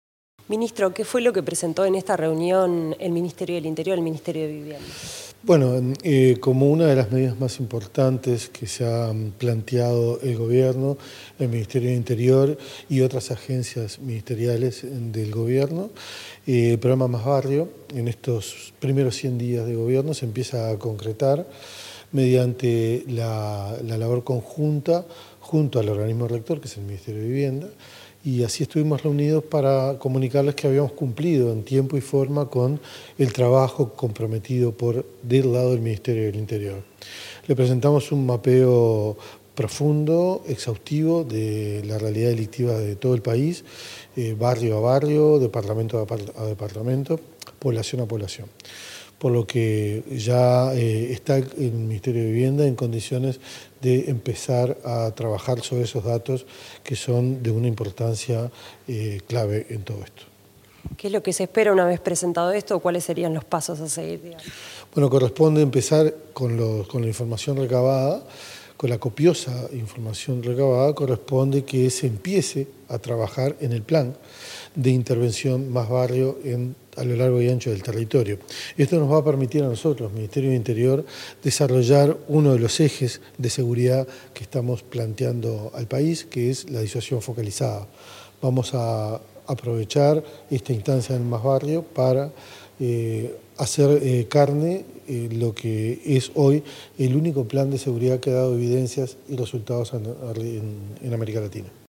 Declaraciones del ministro del Interior, Carlos Negro
El ministro del Interior, Carlos Negro, dialogó con la prensa tras una reunión entre las carteras de Interior y Vivienda, relacionada con el programa